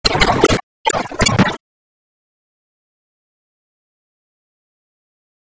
Magnum Sound
magnum sound.mp3